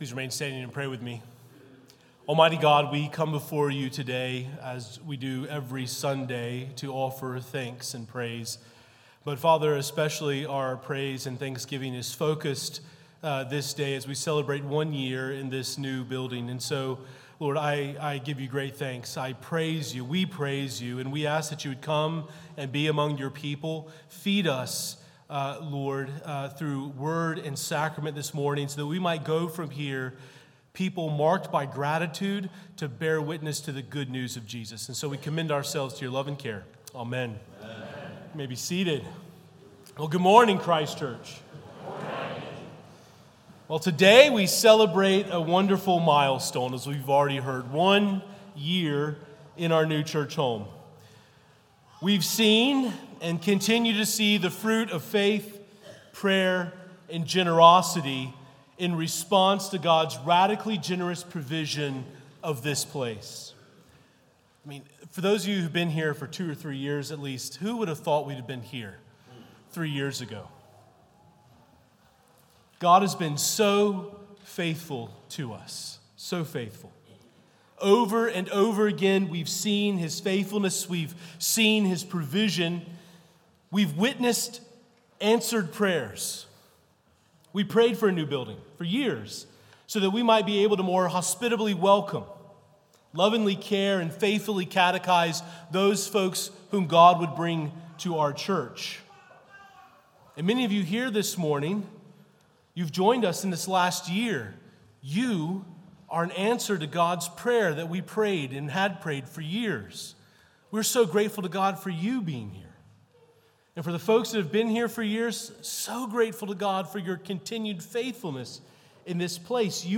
Gratitude Sunday, celebrating one year in the new building.